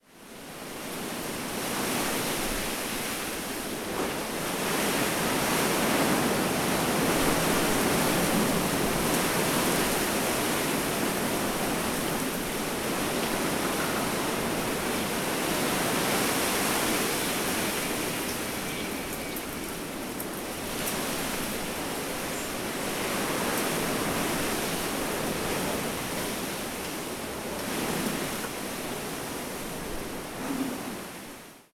Ambiente de viento entre árboles 1
viento
ambiente
arboleda
Sonidos: Rural